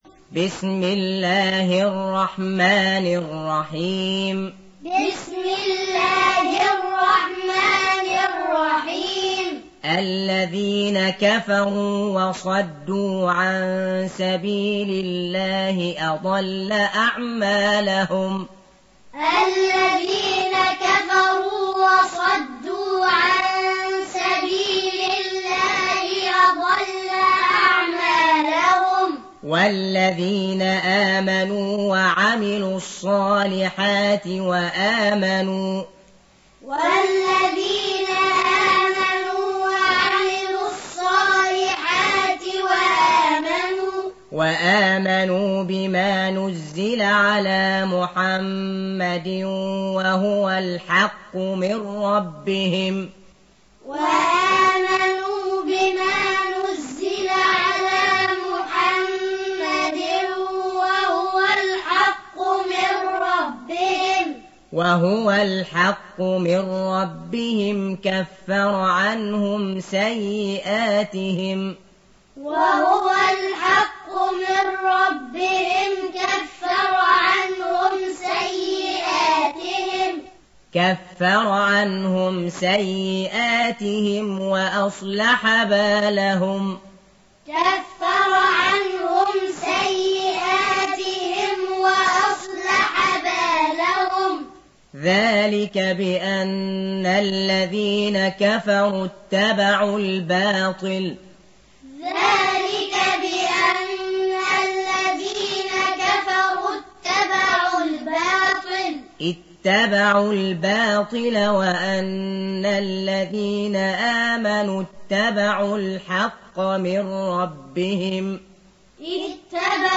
Audio Quran Taaleem Tutorial Recitation
Surah Repeating تكرار السورة Download Surah حمّل السورة Reciting Muallamah Tutorial Audio for 47. Surah Muhammad or Al-Qit�l سورة محمد N.B *Surah Includes Al-Basmalah Reciters Sequents تتابع التلاوات Reciters Repeats تكرار التلاوات